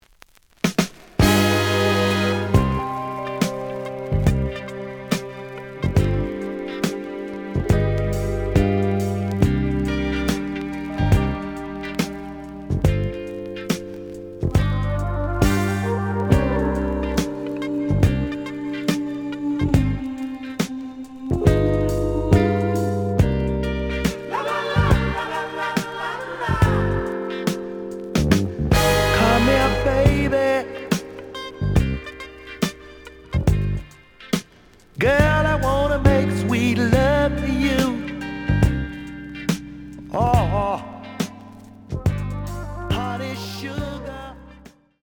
The audio sample is recorded from the actual item.
●Genre: Soul, 70's Soul
Some click noise on B side due to scratches.